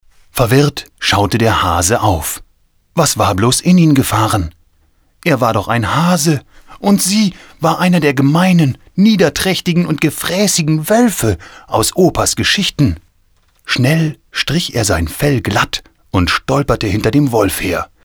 Kein Dialekt
Sprechprobe: eLearning (Muttersprache):
german voice over artist.